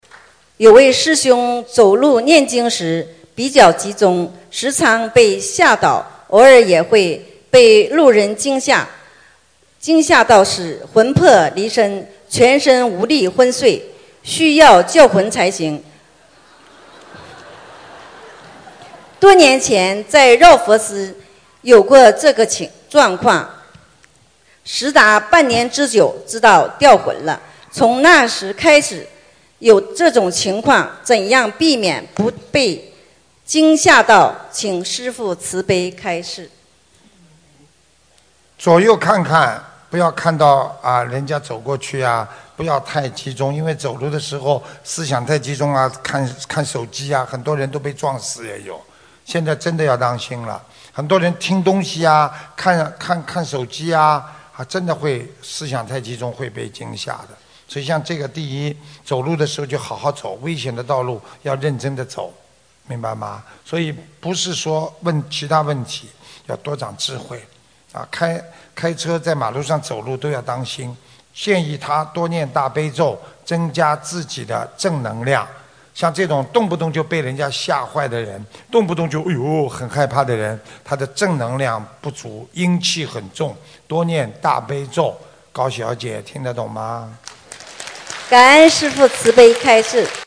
如何避免受惊吓┃弟子提问 师父回答 - 2017 - 心如菩提 - Powered by Discuz!